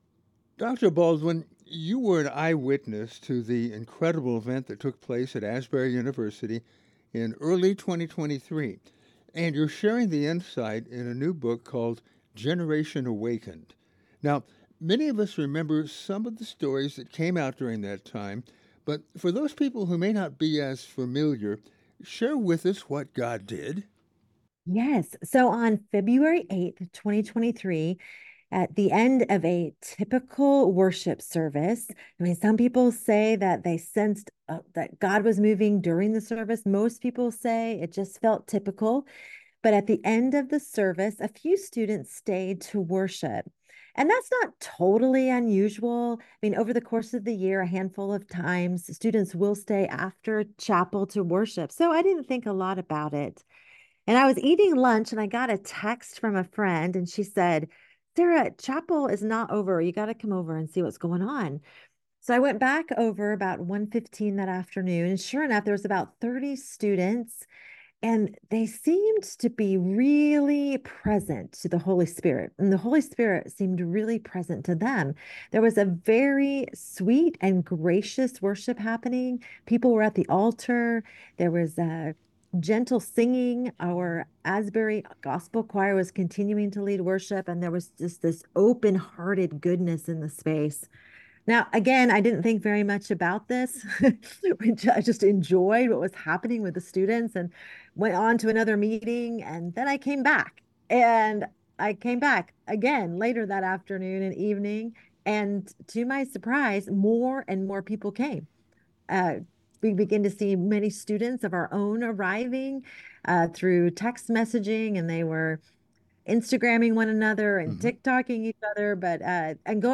(Our complete podcast interview)